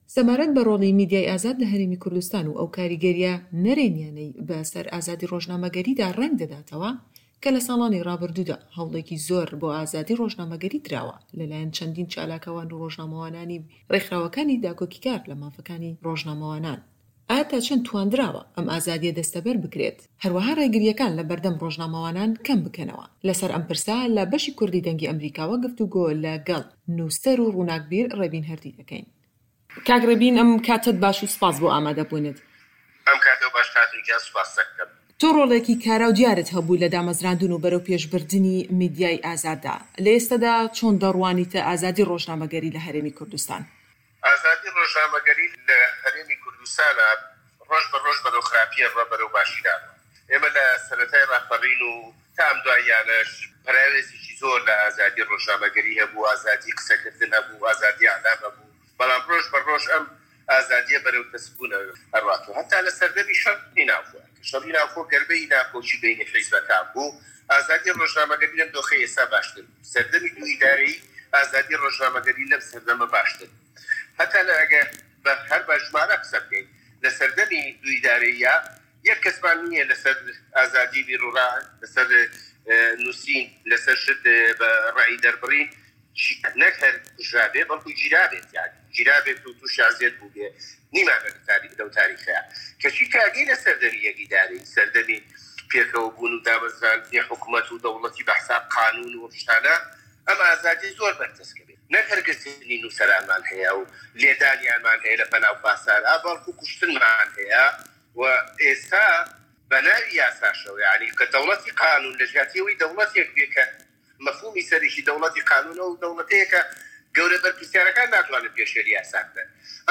هه‌رێمه‌ کوردیـیه‌کان - گفتوگۆکان